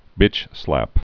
(bĭchslăp) Vulgar Slang